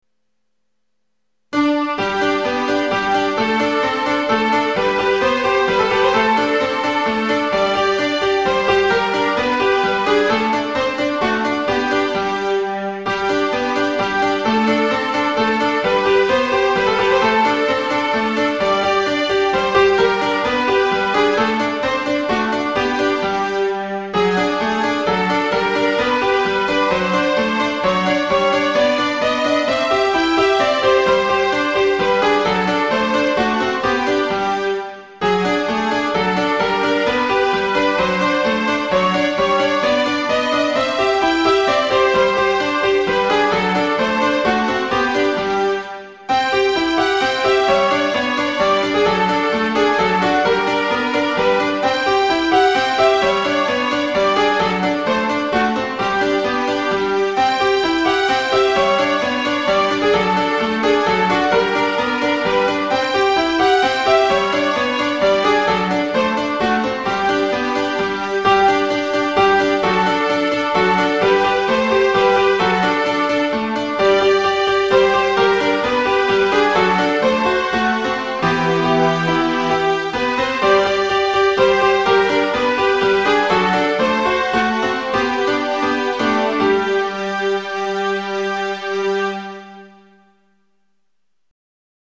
Classical Music Compositions
String Pieces